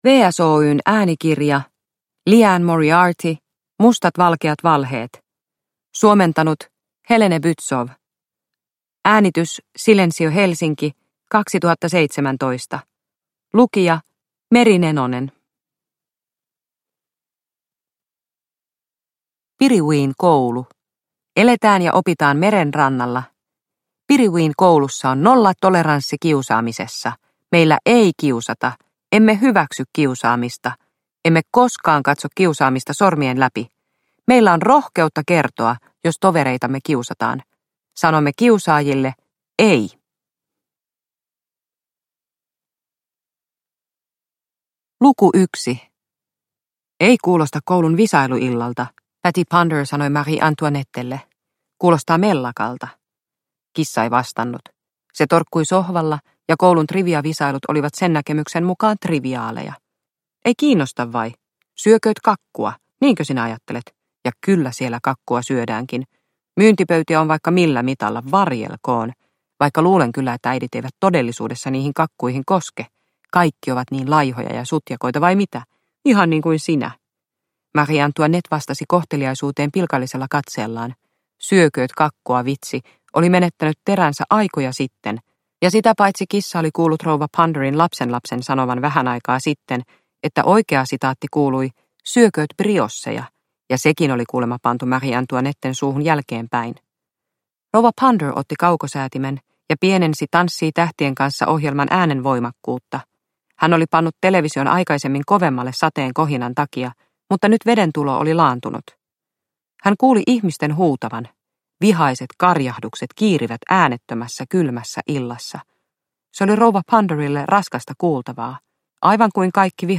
Mustat valkeat valheet – Ljudbok – Laddas ner